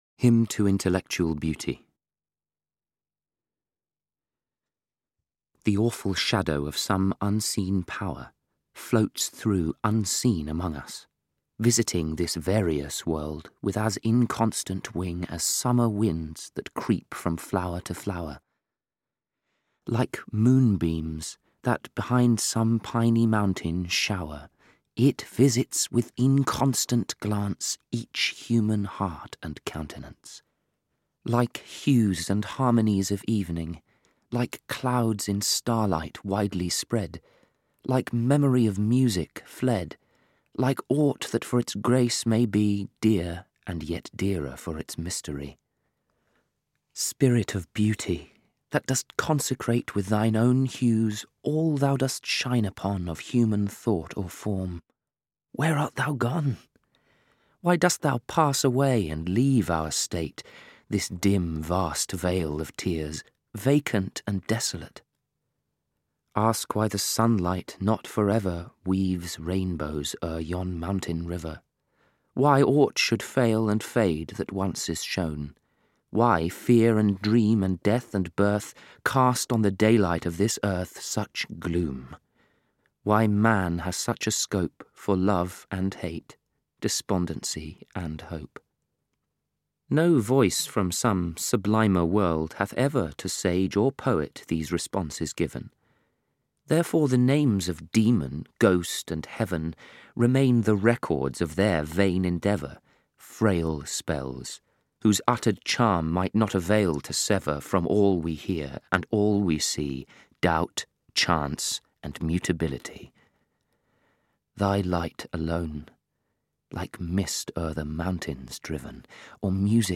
Audiobook The Great Poets – Percy Bysshe Shelley.
Ukázka z knihy
This anthology contains many of his best-known poems, including Ozymandias, The Mask of Anarchy and To a Skylark, as well as excerpts from (among others) Prometheus Unbound and Adonaïs, all read by Bertie Carvel, one of the most talented English actors of his generation.
• InterpretBertie Carvel